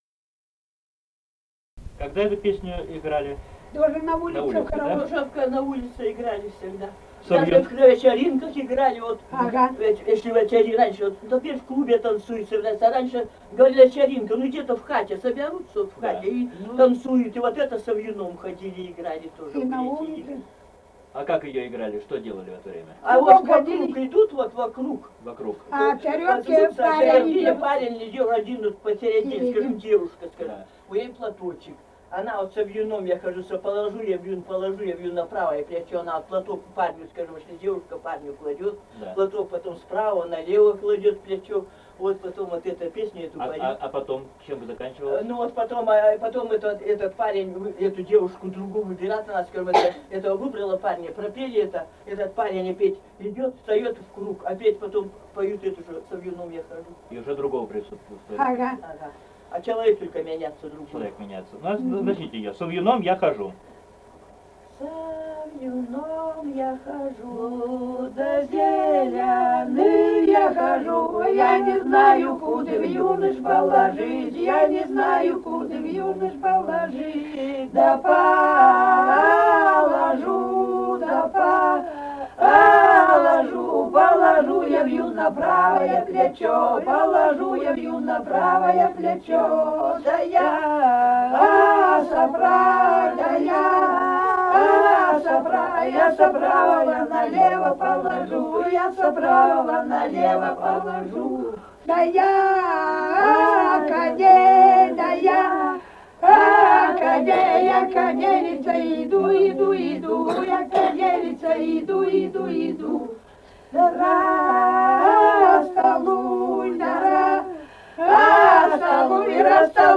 Русские песни Алтайского Беловодья 2 «Со вьюном я хожу», вечёрочная игровая.
Республика Казахстан, Восточно- Казахстанская обл., Катон-Карагайский р-н, с. Урыль (казаки), июль 1978.